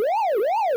pacghost1.wav